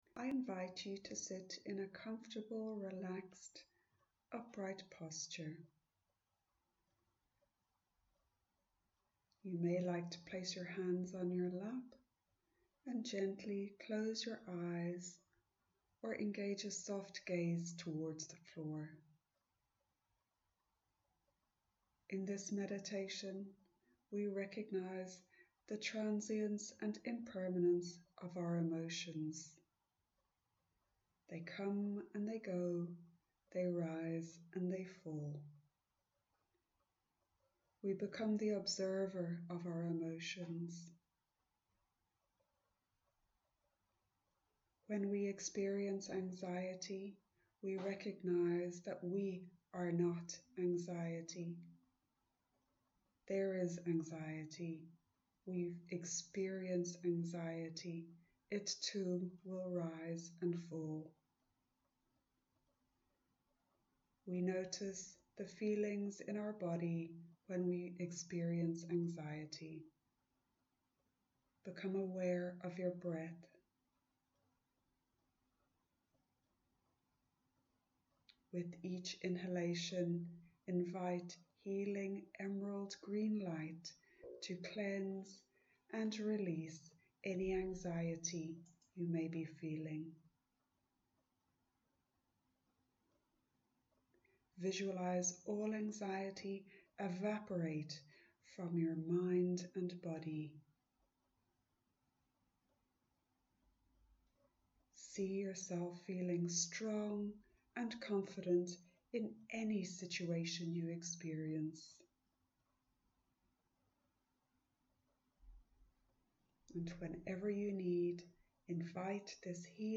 AUDIO MEDITATION